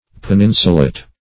peninsulate - definition of peninsulate - synonyms, pronunciation, spelling from Free Dictionary
Search Result for " peninsulate" : The Collaborative International Dictionary of English v.0.48: Peninsulate \Pen*in"su*late\, v. t. [imp.